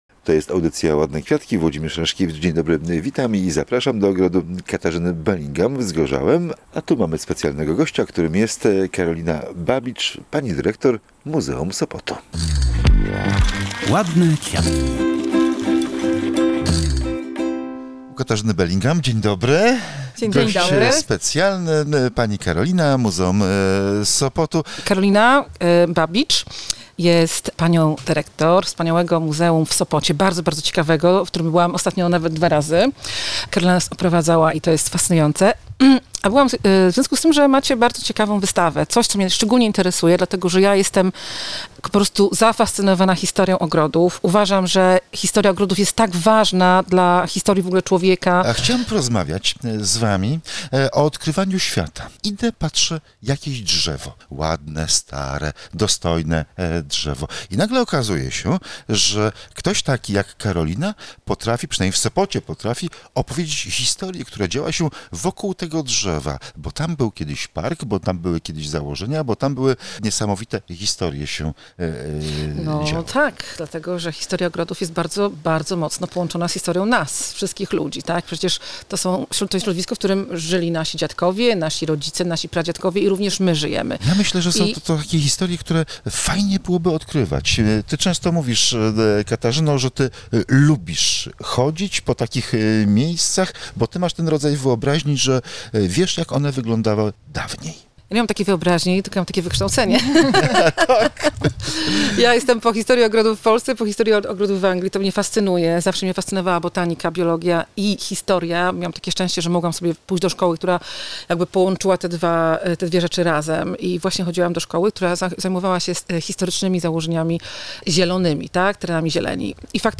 Sopot i jego ogrodnicy sprzed lat – rozmowa o wystawie „Cały świat jest ogrodem”